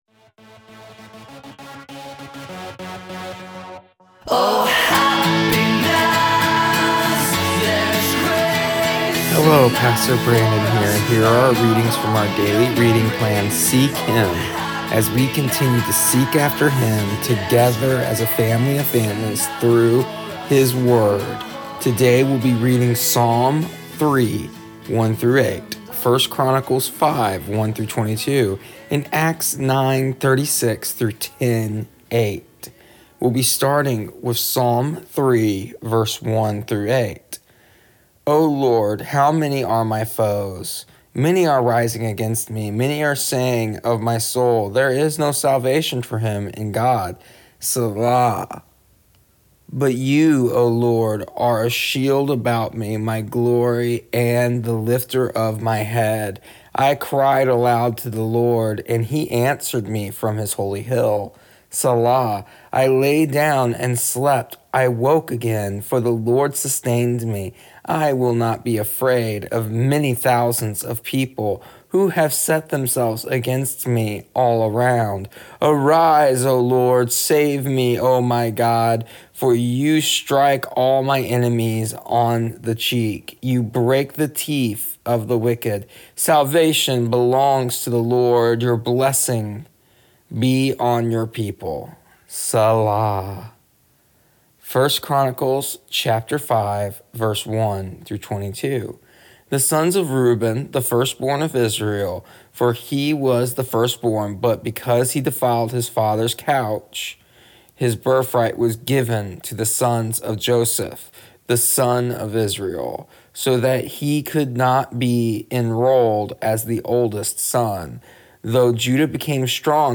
Here is the audio version of our daily readings from our daily reading plan Seek Him for January 4th, 2021.